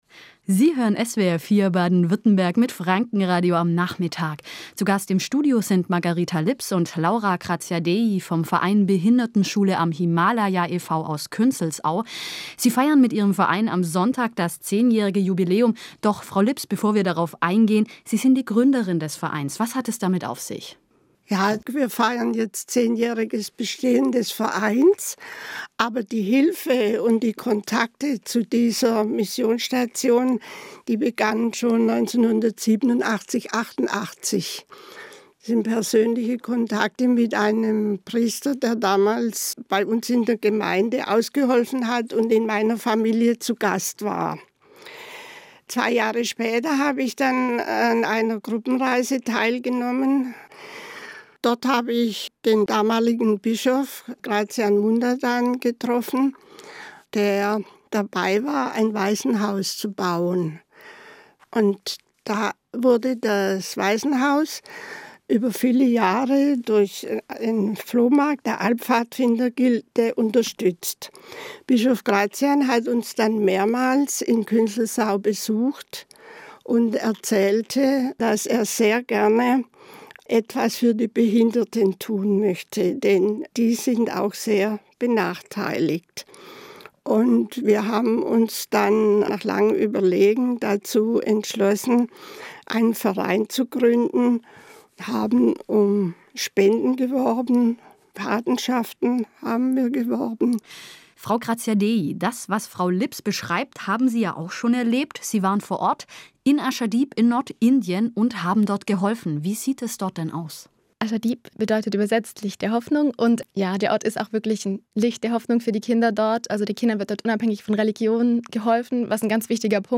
Radio-Interview beim SWR4